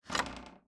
sfx_chest_open_phase_1.ogg